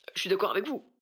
VO_ALL_Interjection_18.ogg